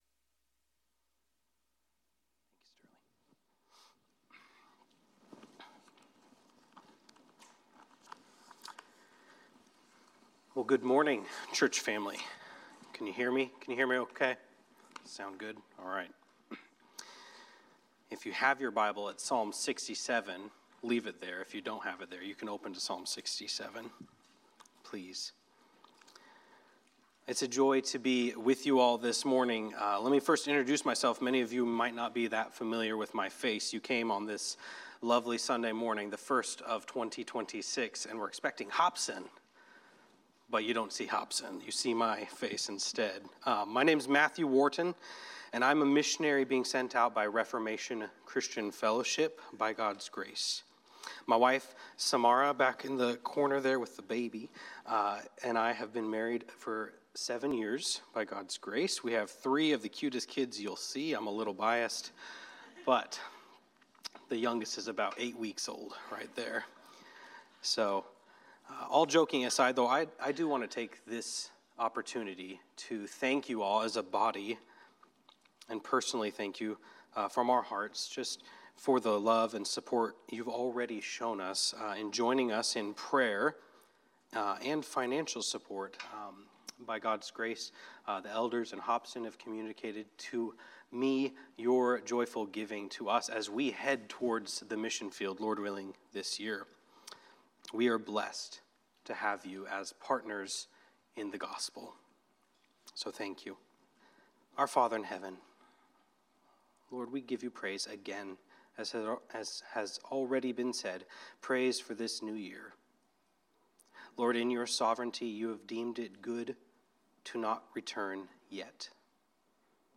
This Week's Sermon
Guest Speaker